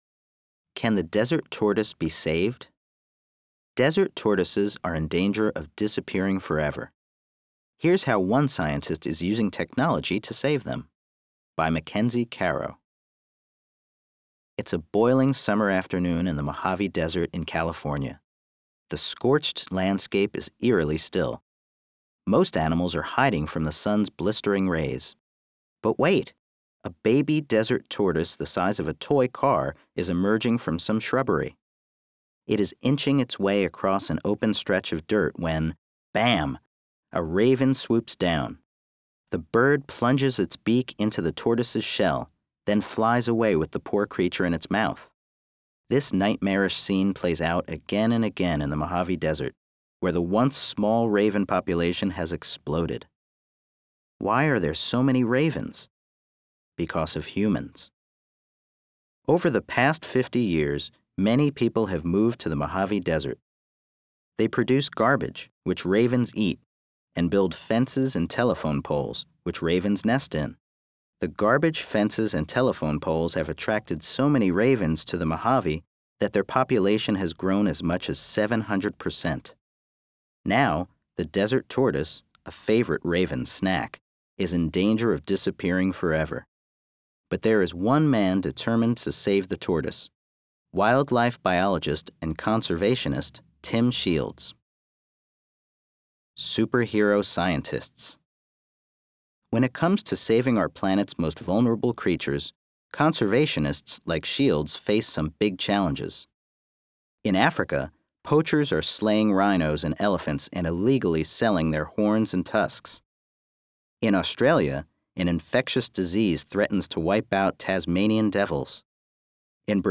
audio version while students follow along in their printed issues.